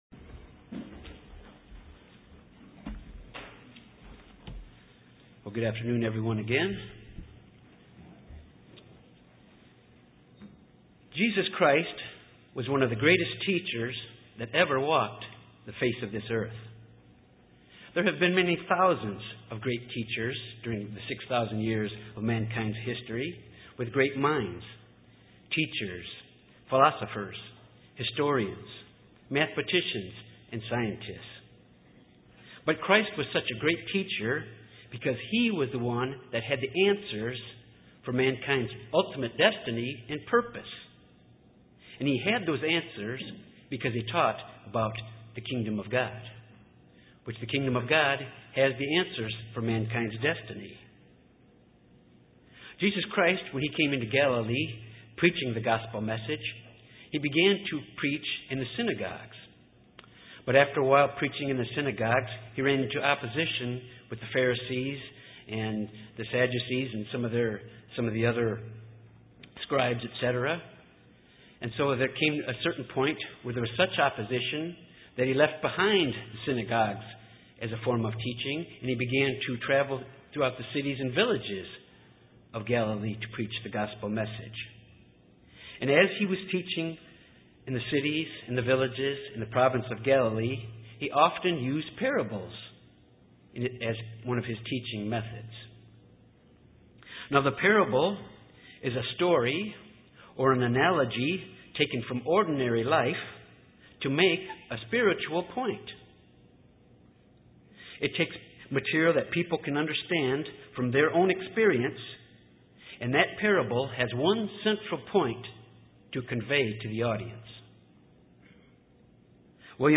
Given in Jonesboro, AR
UCG Sermon Studying the bible?